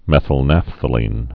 (mĕthəl-năfthə-lēn, -năpthə-)